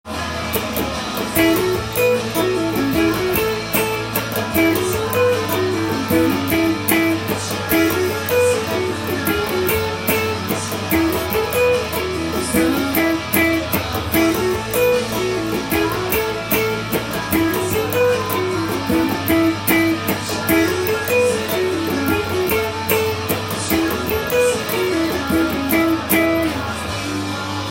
F♯マイナーペンタトニックスケールを使用して
音源にあわせてカッティングしてみました